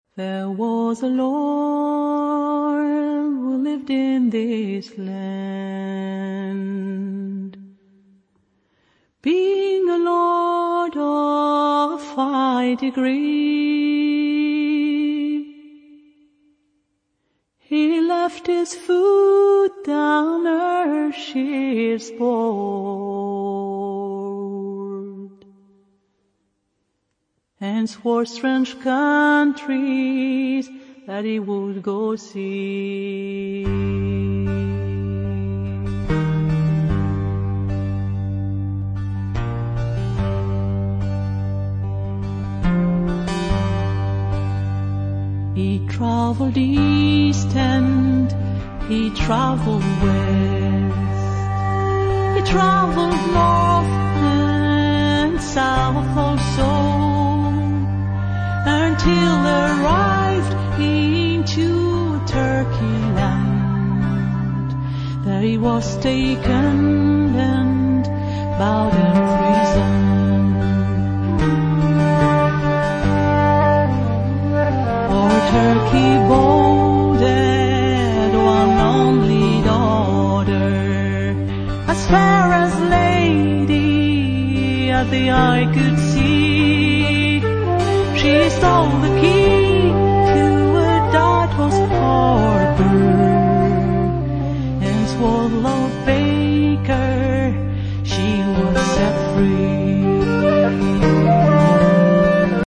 她的聲音應該是來自民謠的訓練，
雖然在尾音的掌控力上有少少的飄移，
但那獨特的音色與溫度——讓人一聽難忘，
另外，這張專輯也用上不少地方樂器，
有些長得像琵琶、像魯特、像揚琴等。